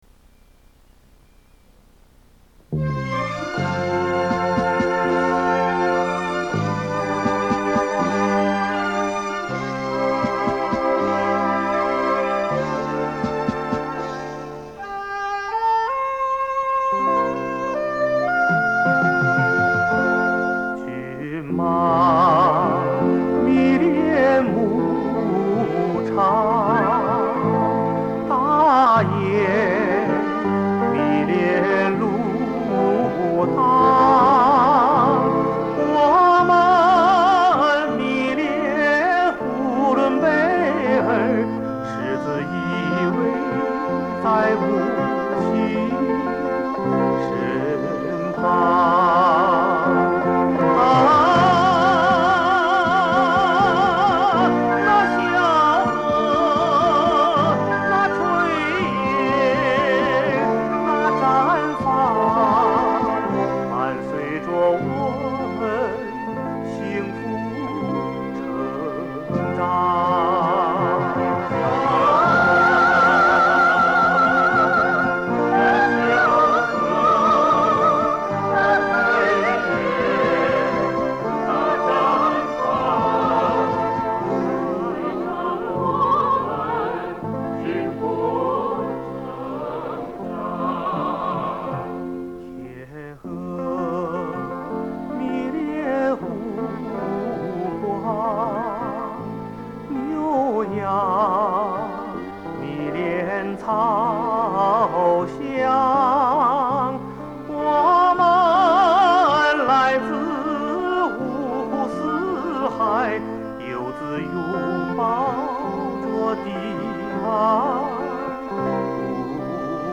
[磁带]